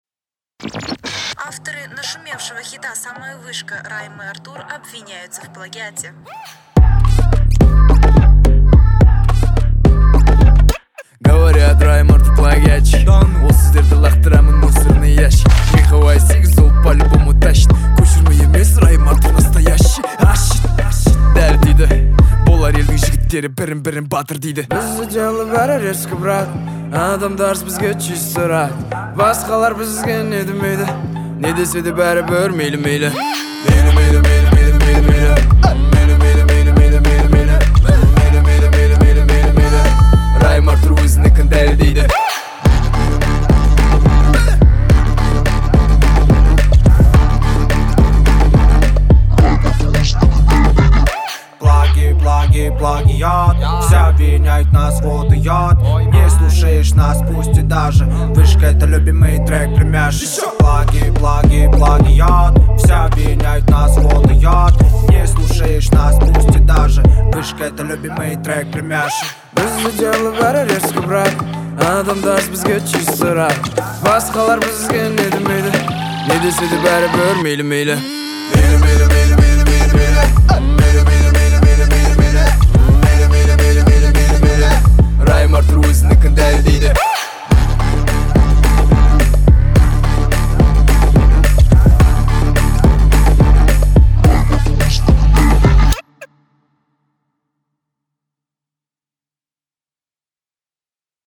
это динамичная и зажигательная композиция в жанре хип-хоп